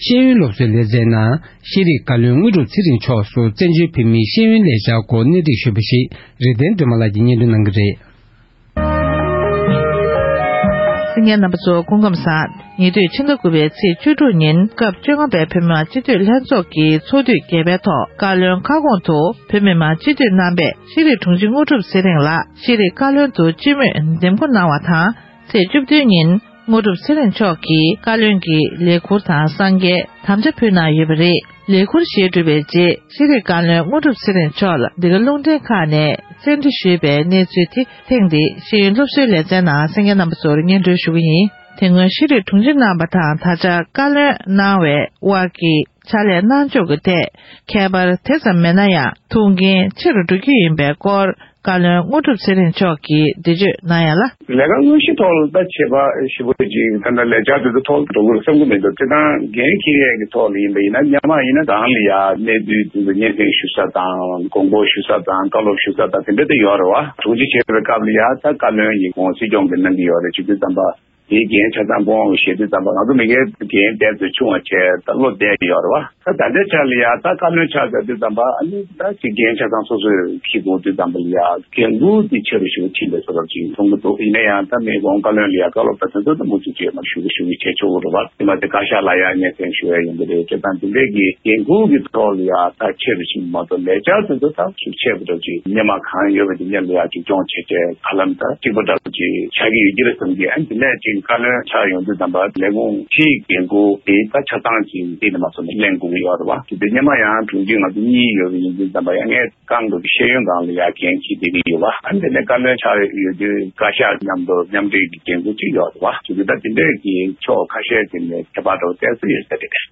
༄༅༎ཐེངས་འདིའི་ཤེས་ཡོན་སློབ་གསོའི་ལེ་ཚན་ནང་དུ་ཤེས་རིག་བཀའ་བློན་དངོས་གྲུབ་ཚེ་རིང་མཆོག་སུ་བཙན་བྱོལ་བོད་མིའི་ཤེས་ཡོན་ལས་འཆར་སྐོར་གནས་འདྲི་ཞུས་པ་ཞིག